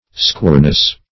Squareness \Square"ness\